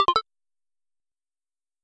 SFX_UI_Equip.mp3